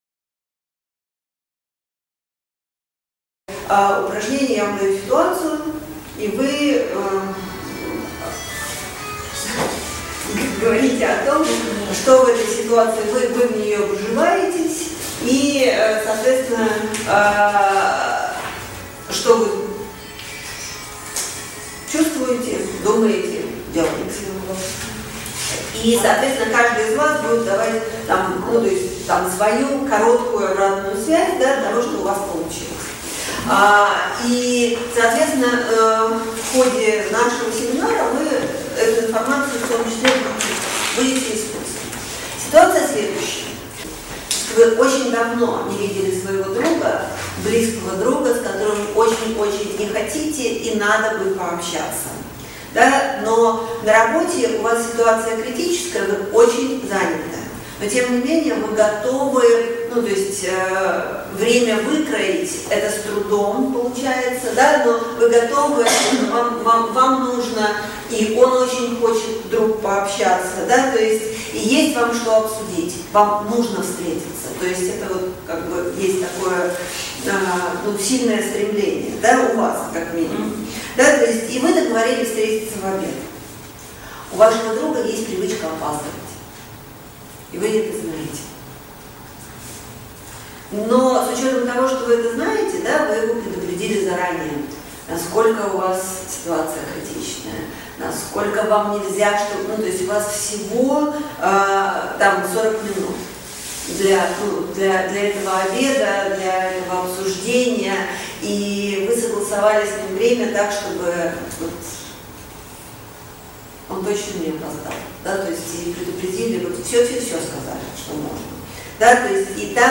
Аудиокнига Три центра эннеаграммы | Библиотека аудиокниг